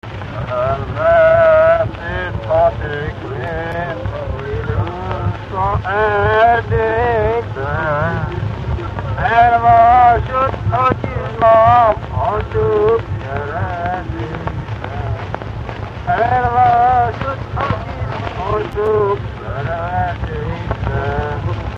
Dunántúl - Somogy vm. - Somogyszob
Stílus: 1.1. Ereszkedő kvintváltó pentaton dallamok
Szótagszám: 6.6.6.6
Kadencia: 4 (5) 1 1